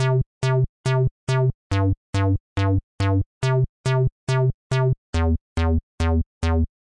描述：C大调中一个非常简单的低音循环，运行在140BPM，使用ctting edge减法合成